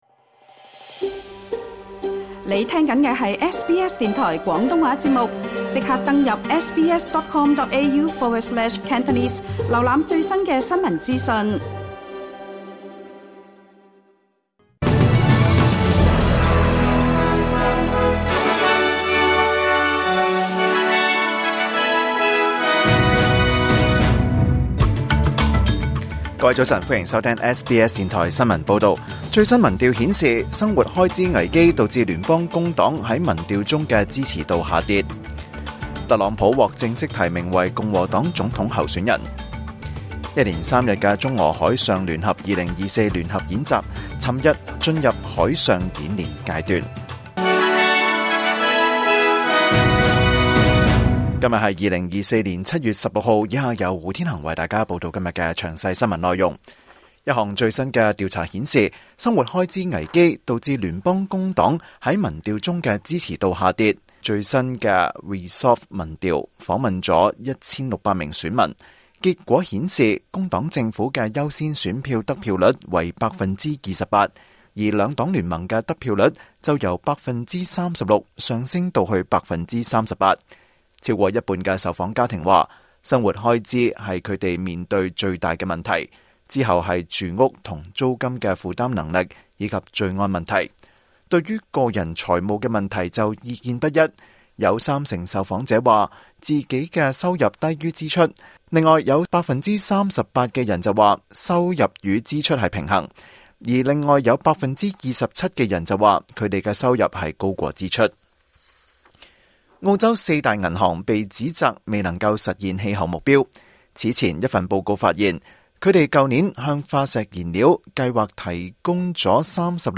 2024年7月16日SBS廣東話節目詳盡早晨新聞報道。